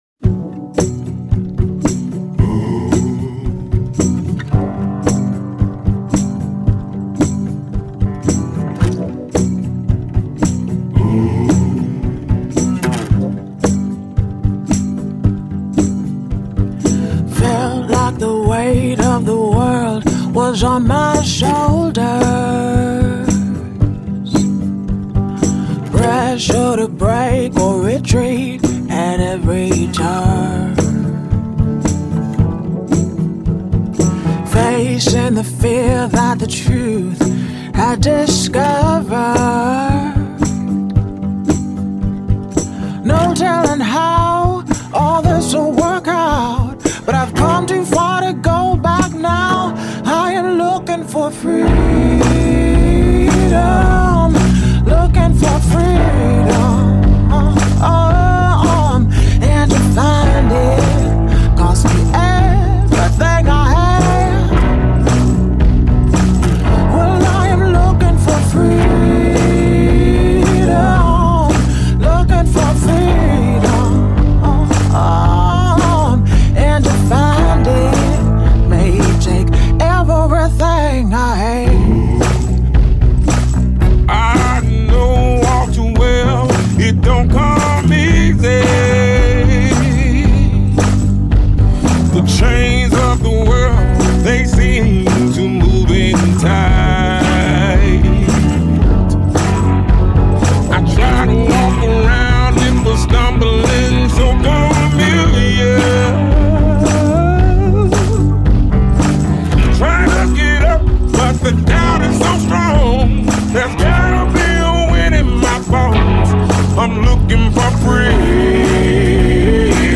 📻 In onda su Radio Città Aperta
🌍 Prima ora Un percorso musicale e cinematografico “in giro per il mondo”, dove brani e frammenti di film si fondono in un unico filo conduttore: consapevolezza, libertà e pensiero critico. Un collage sonoro che attraversa lingue, paesi e generi, per stimolare l’ascolto attivo e la riflessione.